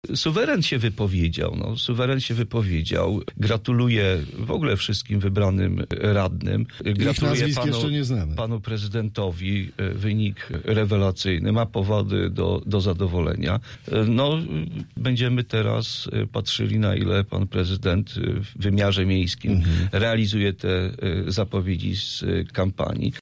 Zbigniew Kościk, radny wojewódzki Prawa i Sprawiedliwości komentuje wyniki wczorajszych wyborów.